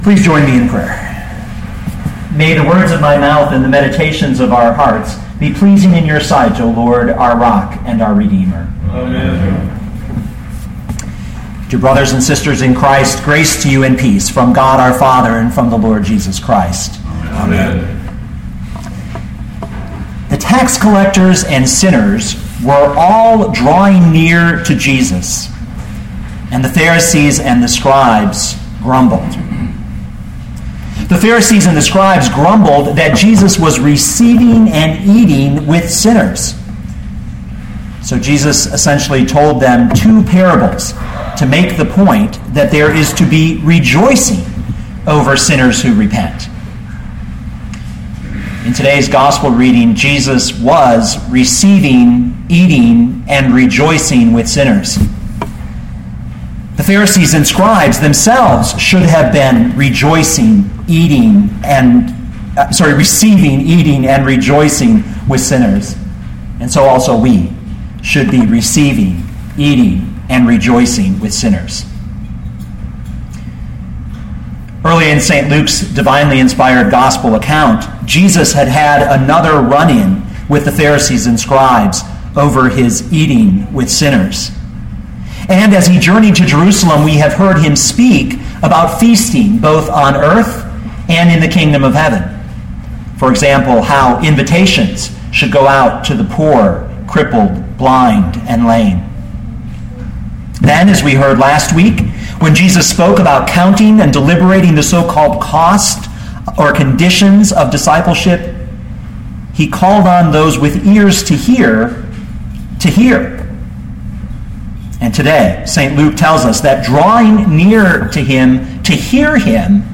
2013 Luke 15:1-10 Listen to the sermon with the player below, or, download the audio.